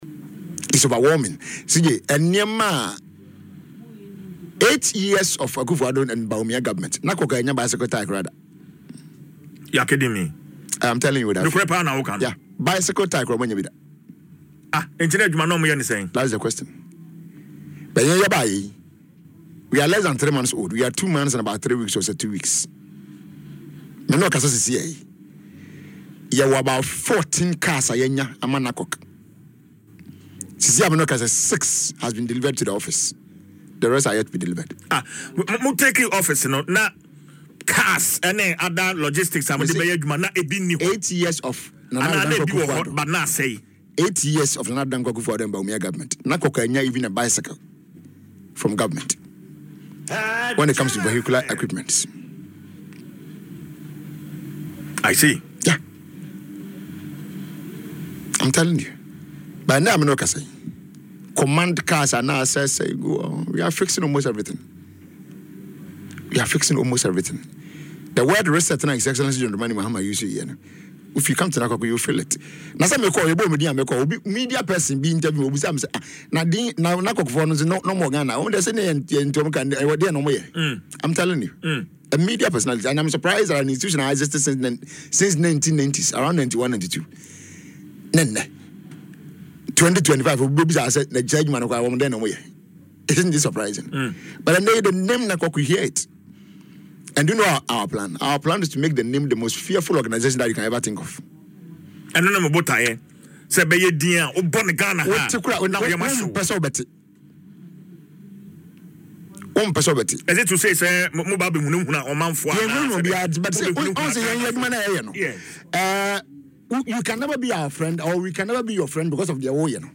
“In those times, NACOC didn’t even receive a bicycle tyre,” he claimed in an interview on Adom FM’s Dwaso Nsem.